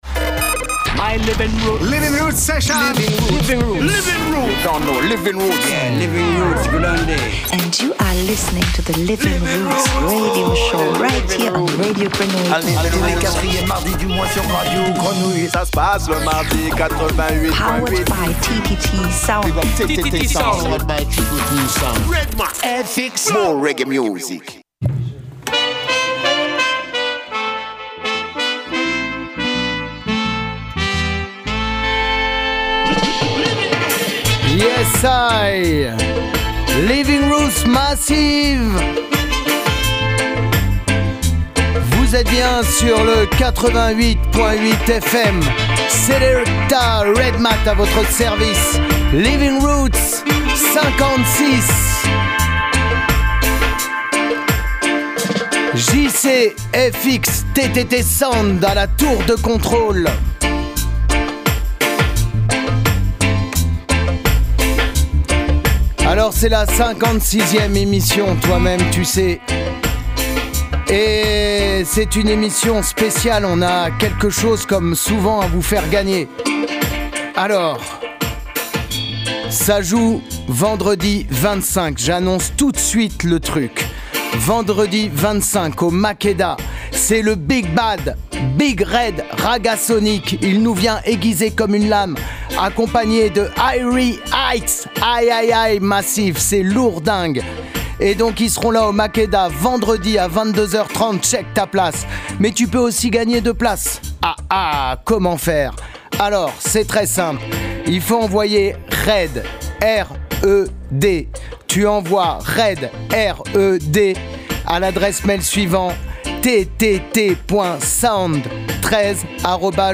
On enchaine avec l’entretien réalisé en compagnie des trois mousquetaires du dubadub, Stand High Patrol, à l’occasion de leur récent concert à Marseille.